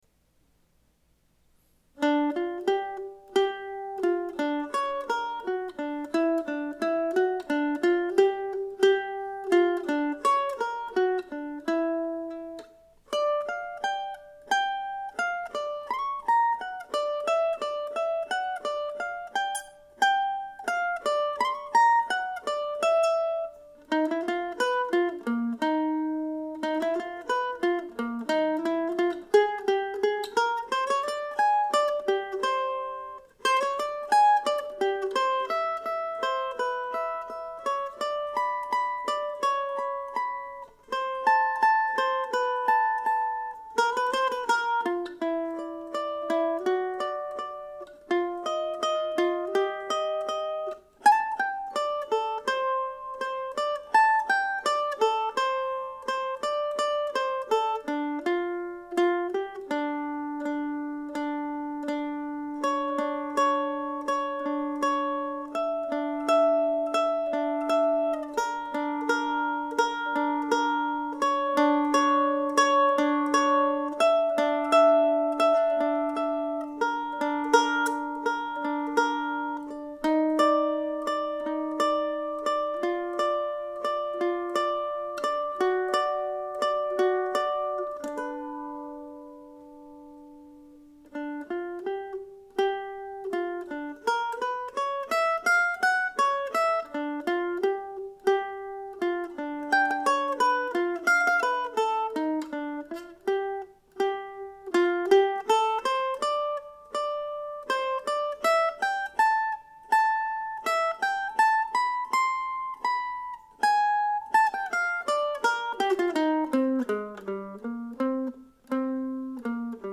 I think now it should go a little faster but, for today, it is fine at this speed.